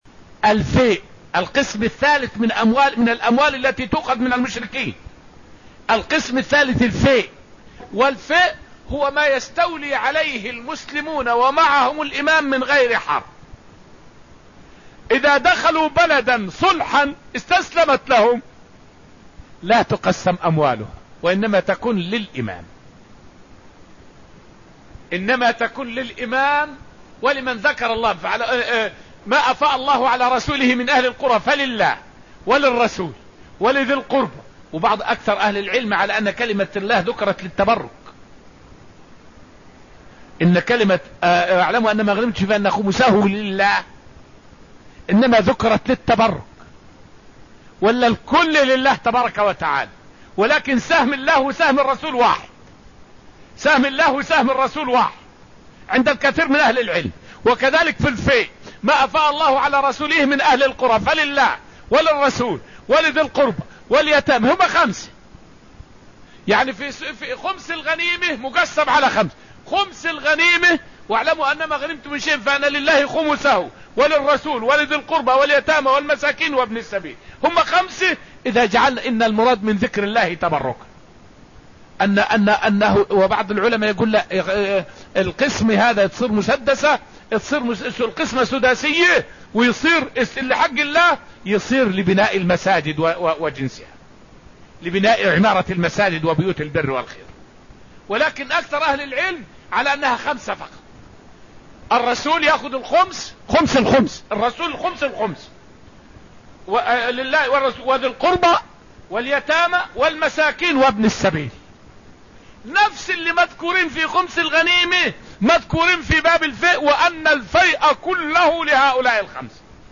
فائدة من الدرس الثاني من دروس تفسير سورة الحديد والتي ألقيت في المسجد النبوي الشريف حول تدريب الجيوش على السمع والطاعة.